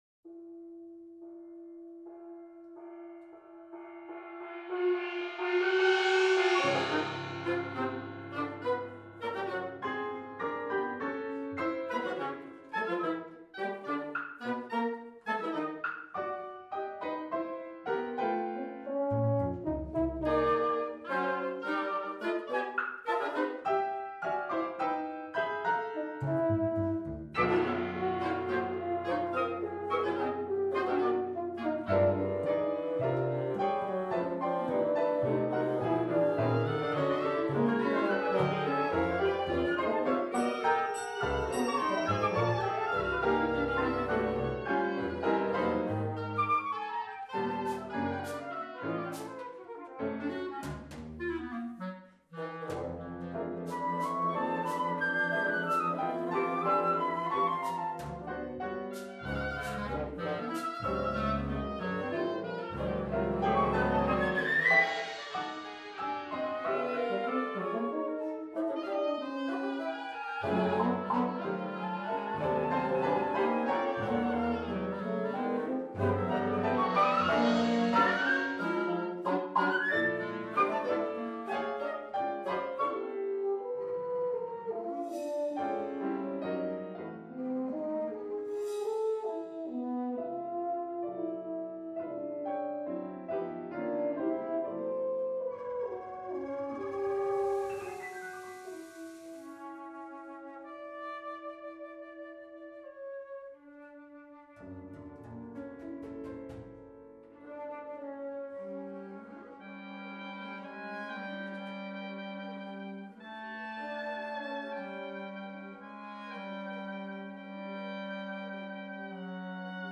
flute
oboe
clarinet
bassoon
horn
piano
percussion